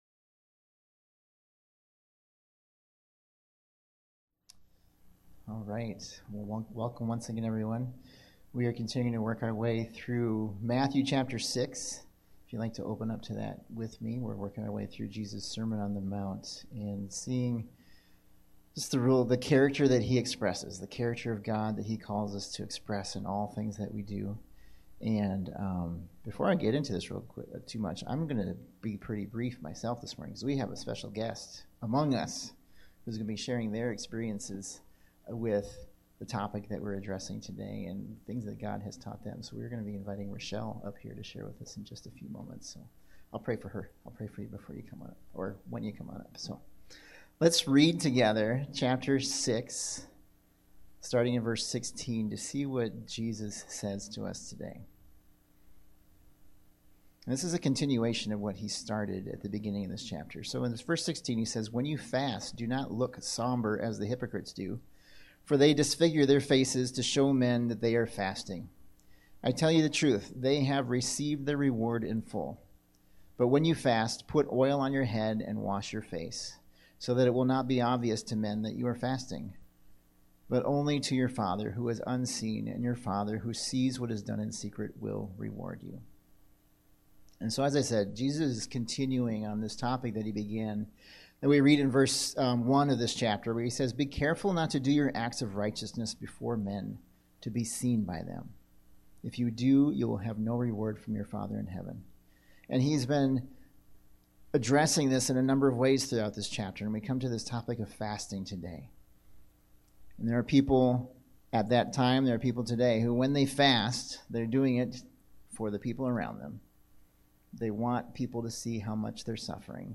True North Church-Cannon Falls: The Sermon on the Mount: Fasting for the Lord